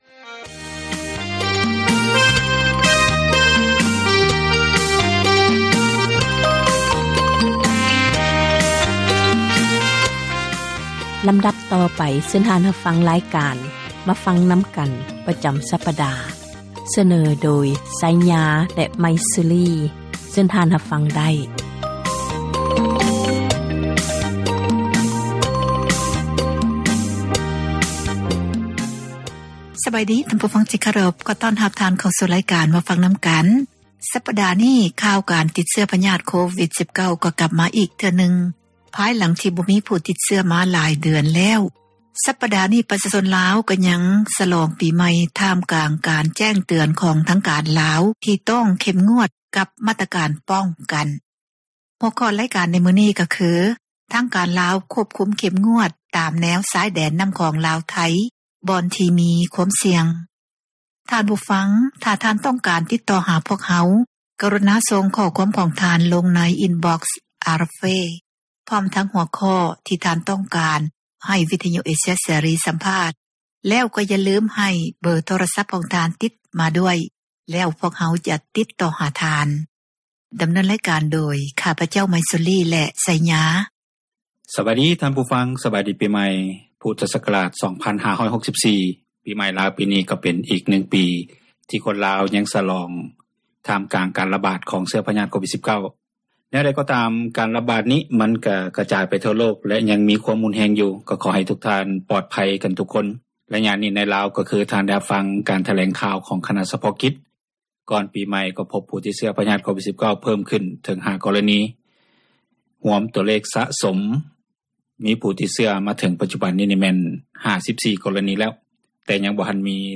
ການສົນທະນາ